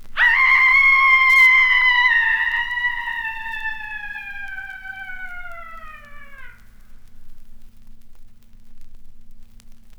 • one long scream fading away - female.wav
one_long_scream_fading_away_-_female_FxJ.wav